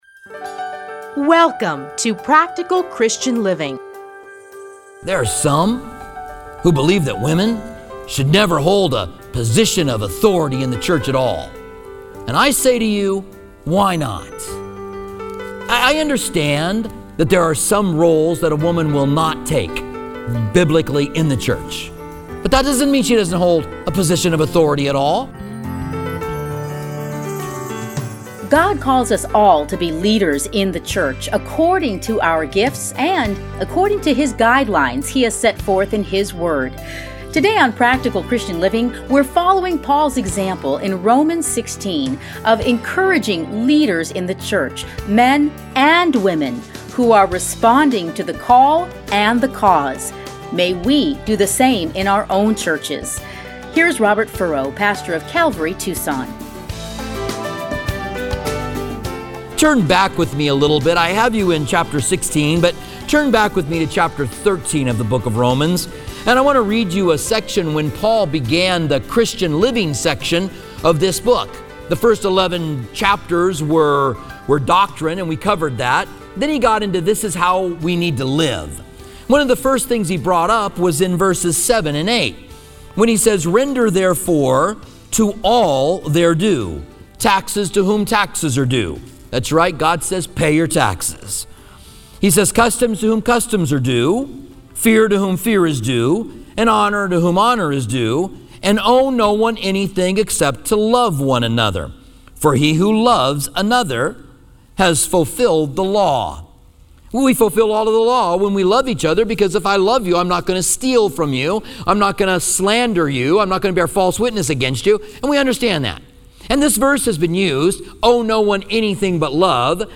edited into 30-minute radio programs titled Practical Christian Living